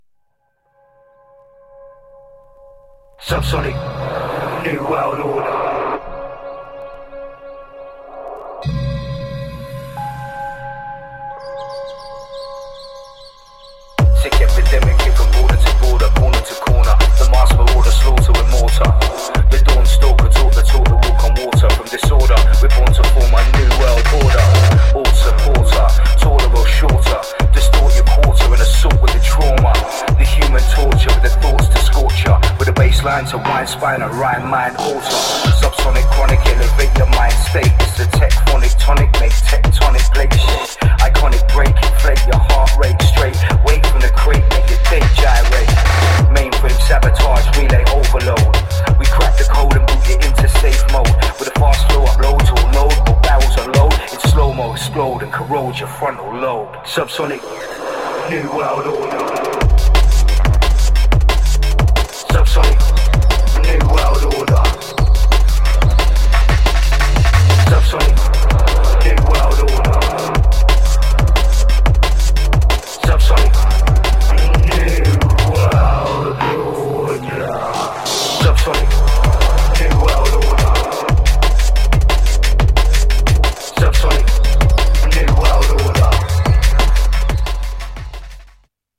Styl: Dub/Dubstep, Drum'n'bass, Jungle/Ragga Jungle